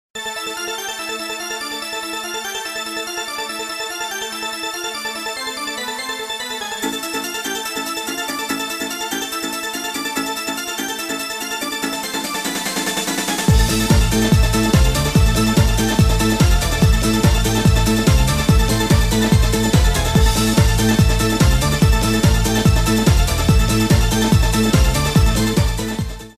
• Качество: 128, Stereo
громкие
dance
электронная музыка
без слов
дискотека 90-х